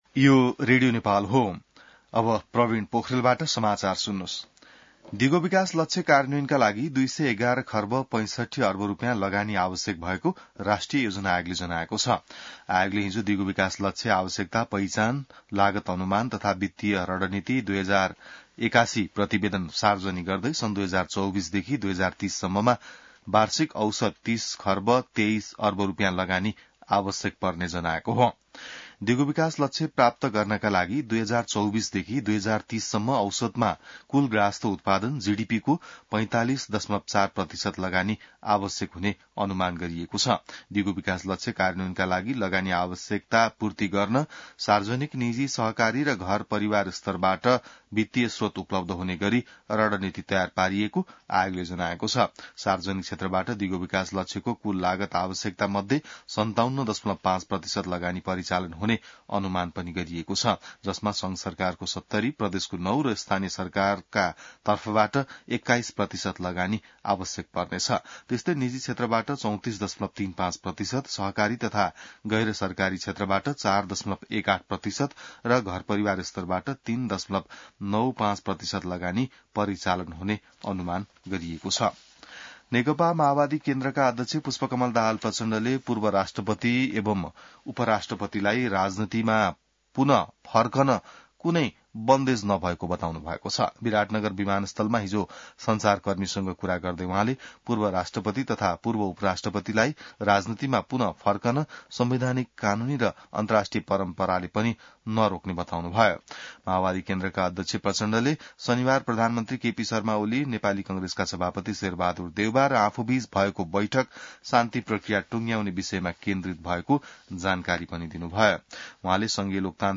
बिहान ६ बजेको नेपाली समाचार : ८ वैशाख , २०८२